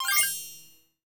Coins (19).wav